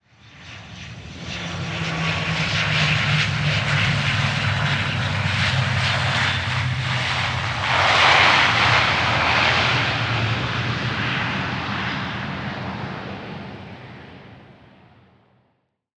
دانلود صدای سوت هواپیما 7 از ساعد نیوز با لینک مستقیم و کیفیت بالا
جلوه های صوتی